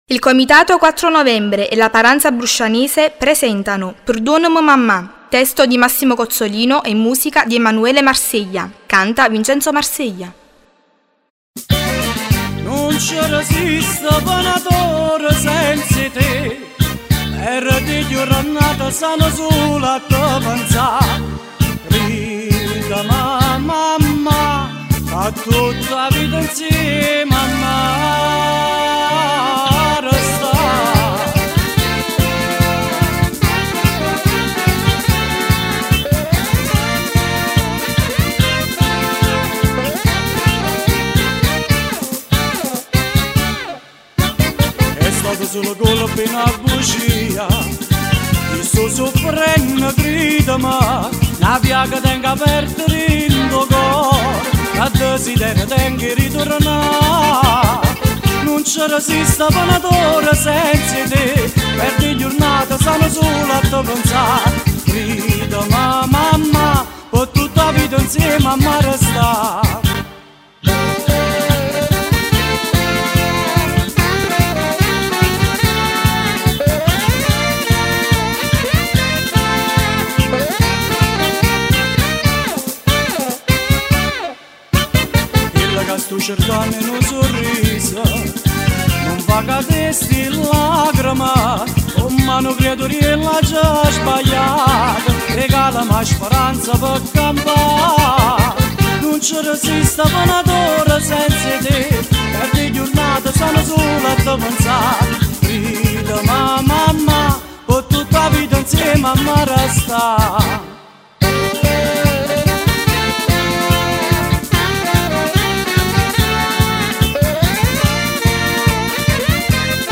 Paranza Bruscianese